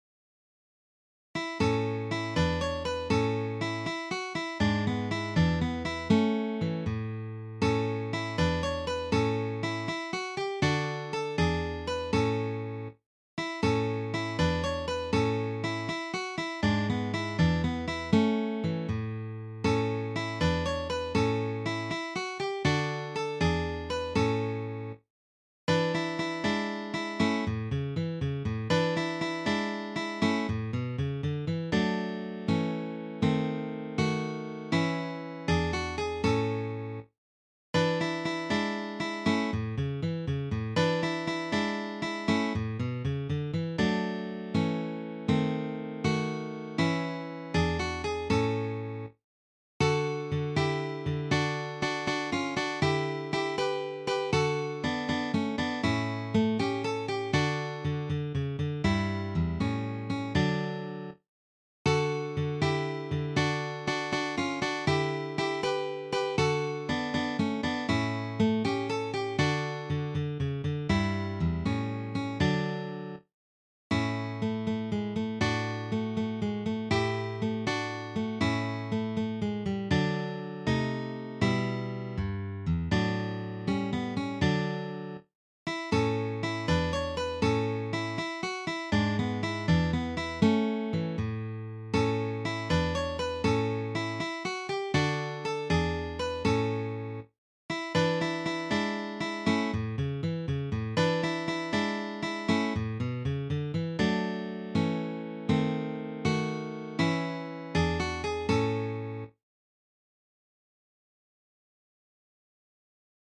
arranged for three guitars